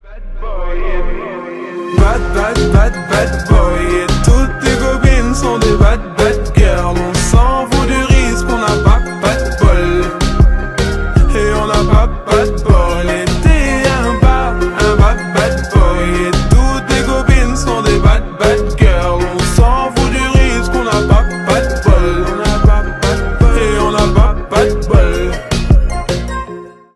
Ремикс
грустные